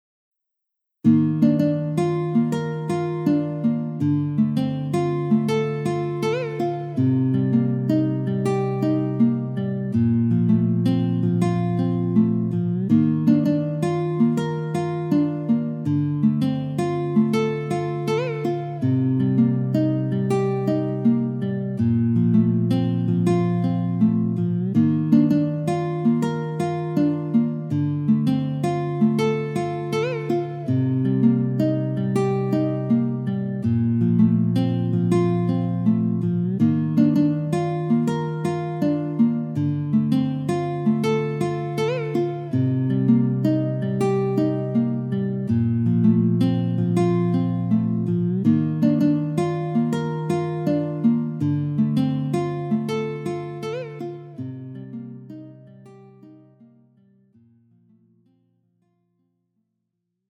음정 원키 4:11
장르 가요 구분 Pro MR